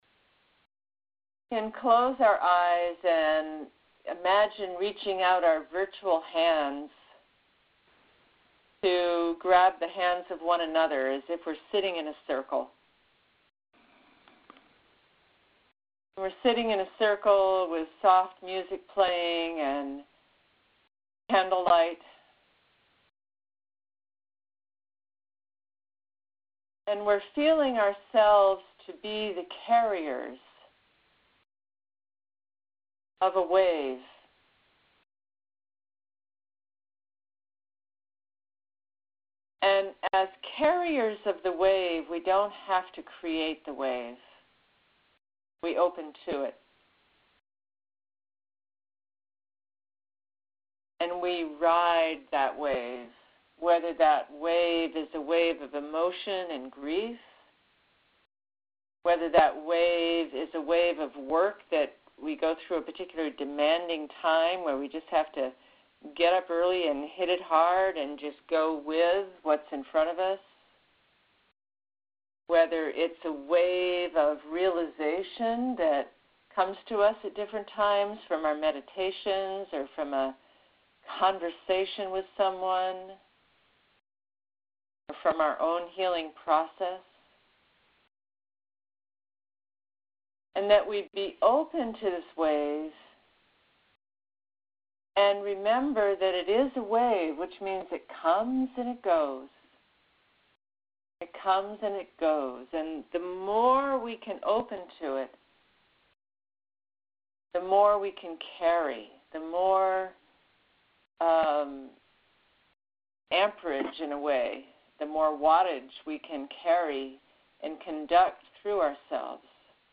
Use the Player Below to Hear the Personal Meditation from Anodea
Anodea_Judith_meditation12-12-download.mp3